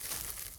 rustle.wav